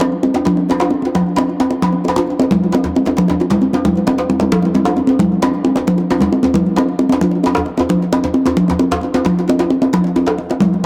CONGABEAT8-L.wav